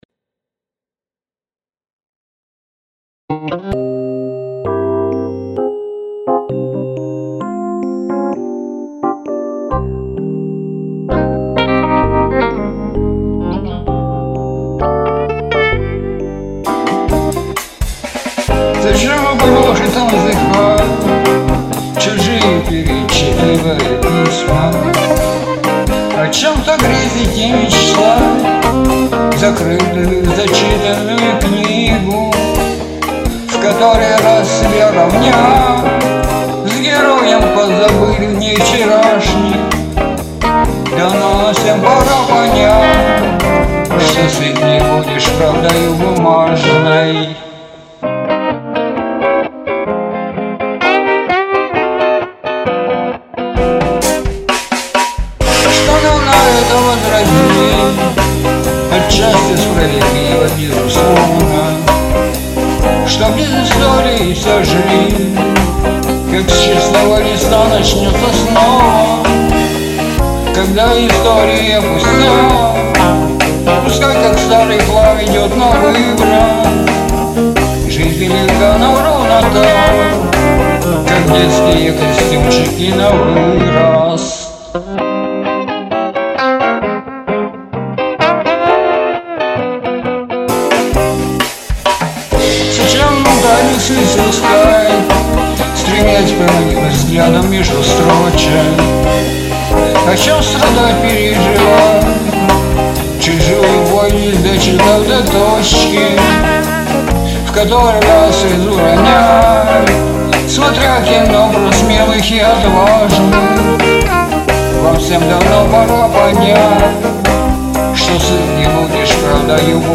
Фанк (337)